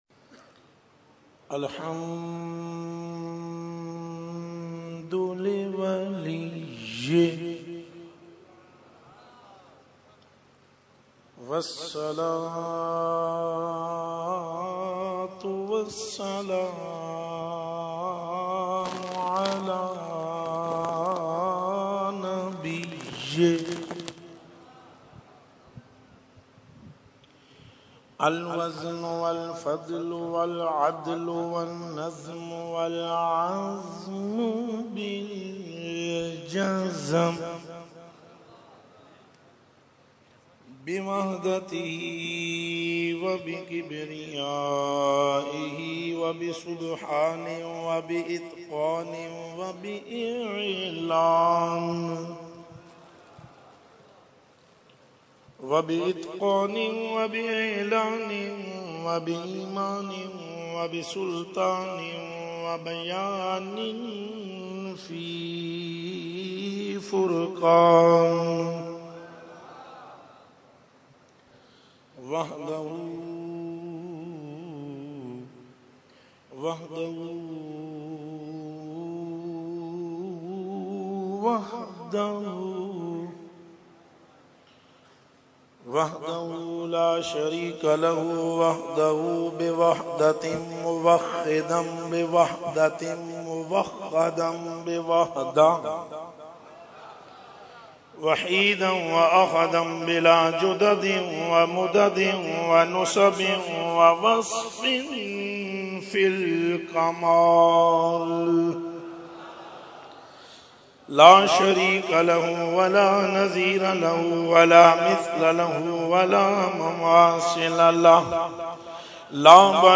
Mediaa: Urs e Makhdoom e Samnani 2015
Category : Speech | Language : UrduEvent : Urs e Makhdoom e Samnani 2015